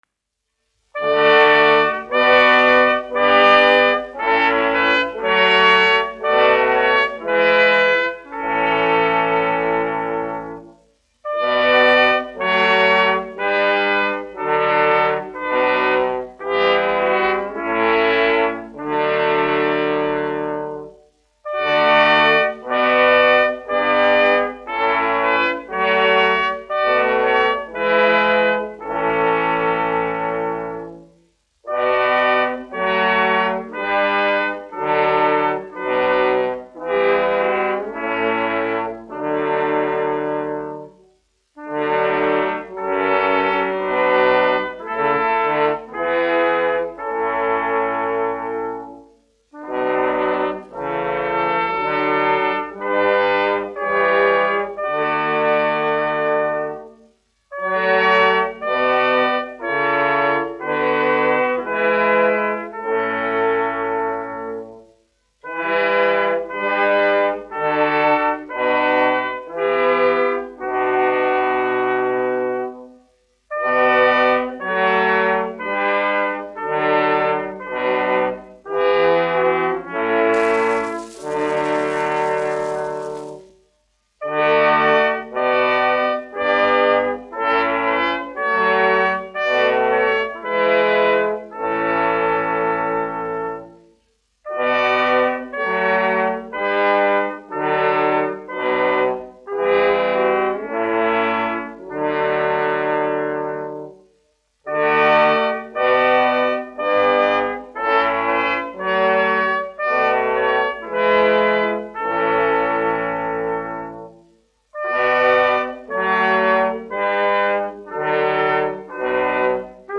Latvijas Nacionālā opera Pūtēju kvartets, izpildītājs
1 skpl. : analogs, 78 apgr/min, mono ; 25 cm
Korāļi
Pūšaminstrumentu kvarteti
Skaņuplate